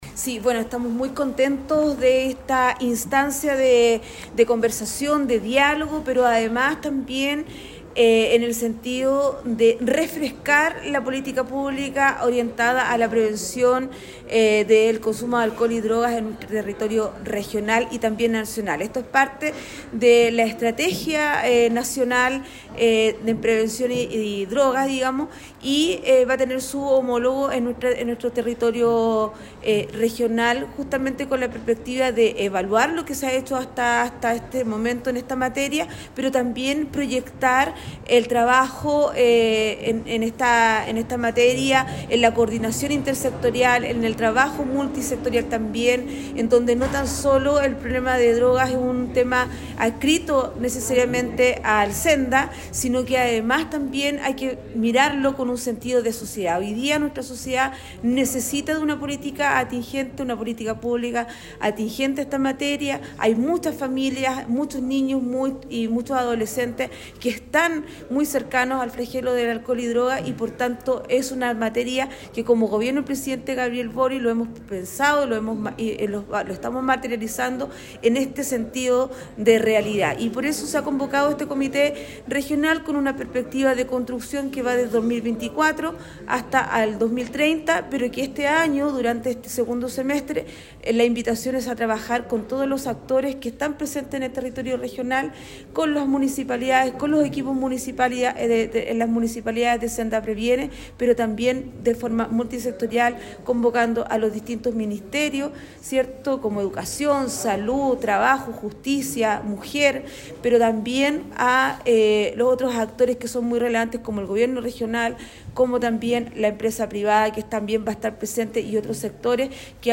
Cuña-Delegada-Presidencial-Regional-Carla-Peña.mp3